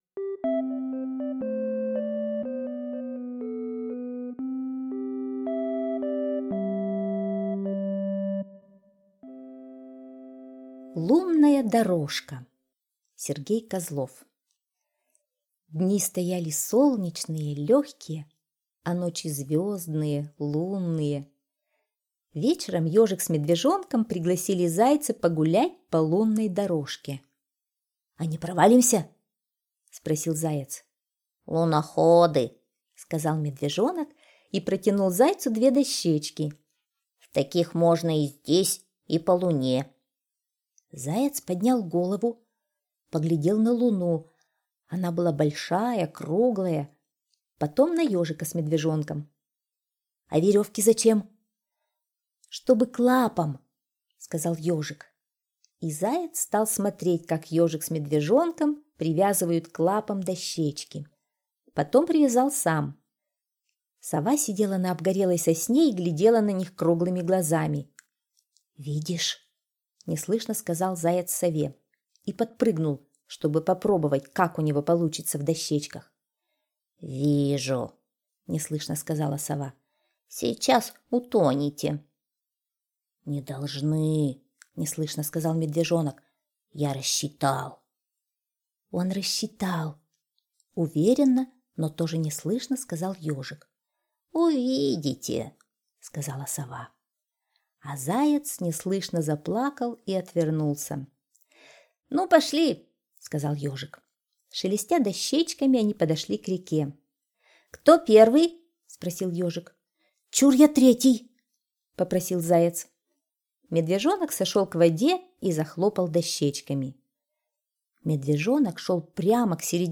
Слушайте Лунная дорожка - аудиосказка Козлова С.Г. Сказка про то, как Ежик, Медвежонок и Заяц решили ночью пройти по реке по лунной дорожке.